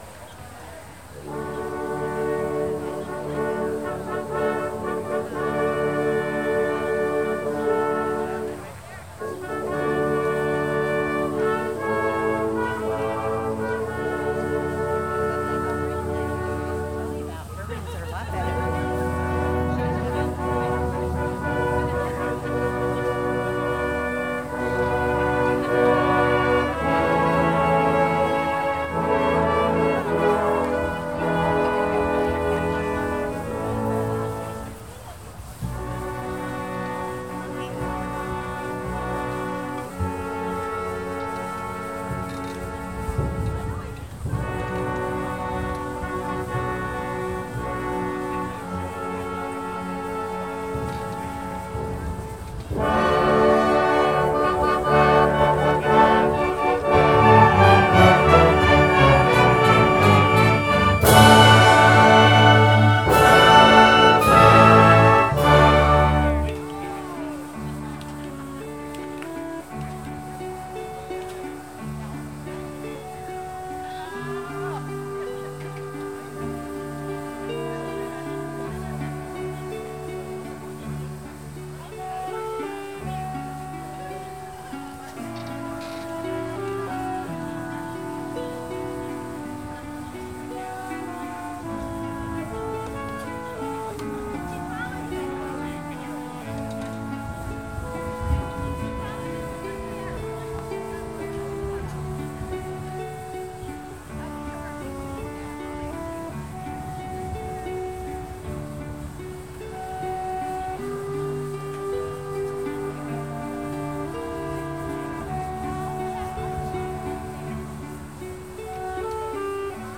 Listing of music recorded by Long Beach Community Band groups.